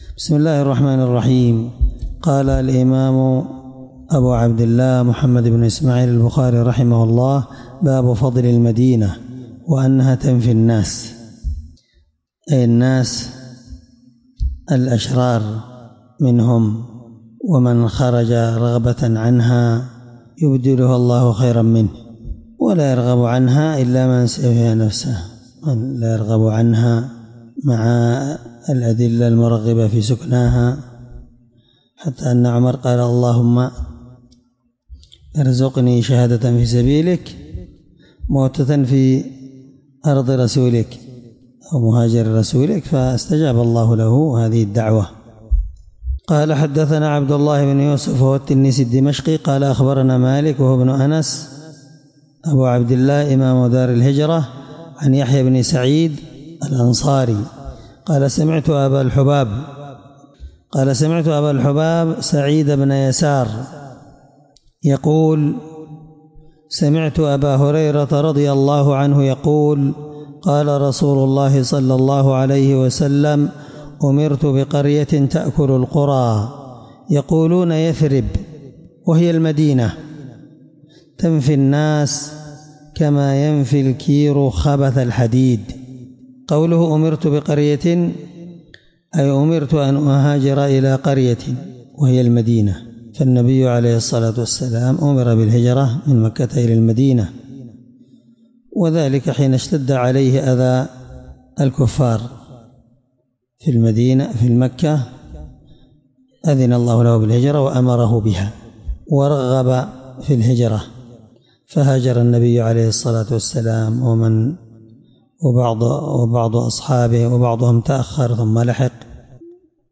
الدرس3من شرح كتاب فضائل المدينة حديث رقم(1871 )من صحيح البخاري